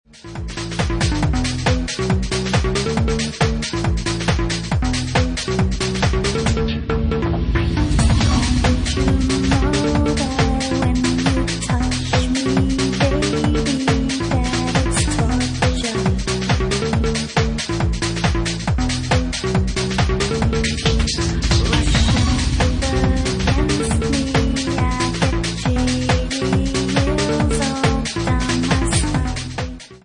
Genre:Bassline House
4x4 / Garage at 138 bpm